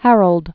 (hărəld) Known as "Harold Harefoot."